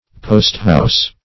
Posthouse \Post"house`\, n.